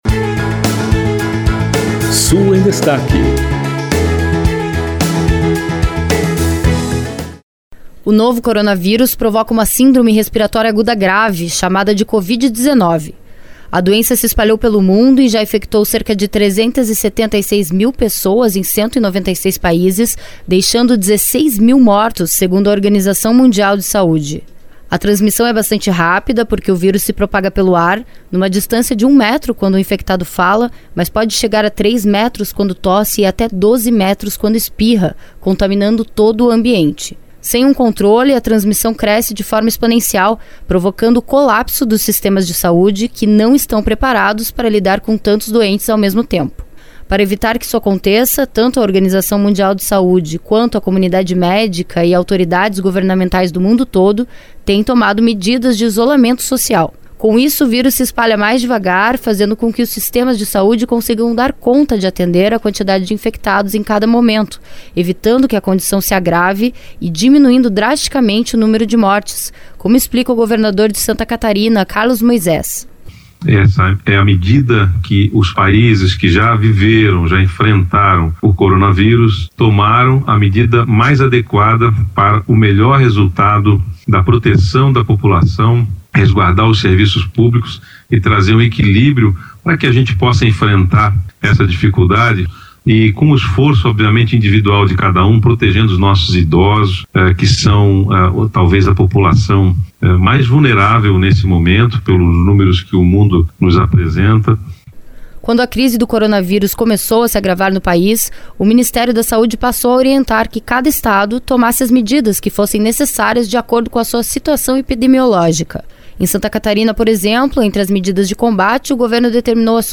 Para evitar que isso aconteça, tanto a Organização Mundial de Saúde, quanto a comunidade médica, quanto autoridades governamentais do mundo todo tem tomado medidas de isolamento social. Com isso, o vírus se espalha mais devagar, fazendo com que os sistemas de saúde consigam dar conta de atender a quantidade de infectados em cada momento, evitando que a condição se agrave e diminuindo drasticamente o número de mortes, como explica o governador de Santa Catarina, Carlos Moisés.
Segundo o governador Carlos Massa Ratinho Junior, não há perigo de desabastecimento da população.